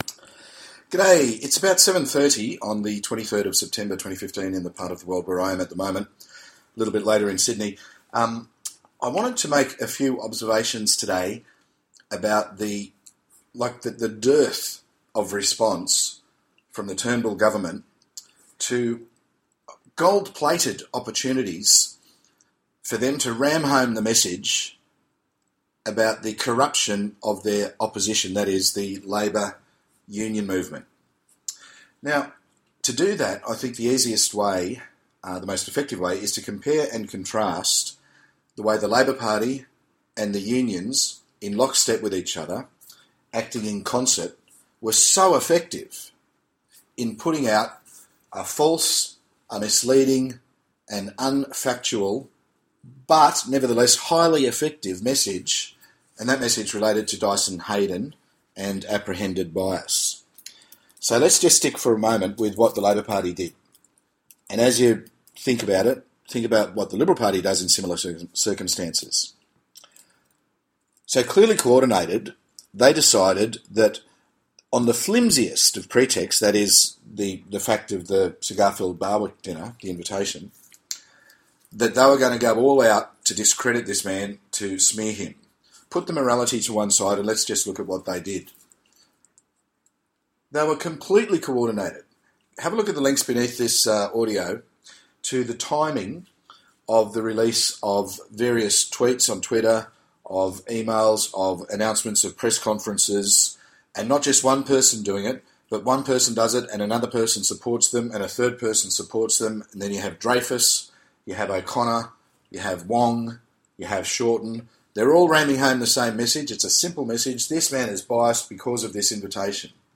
editorial on the LNP response